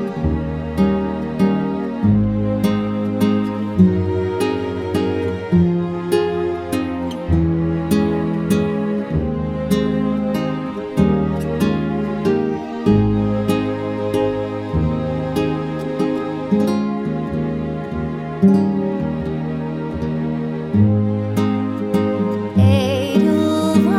Duet Version Soundtracks 2:16 Buy £1.50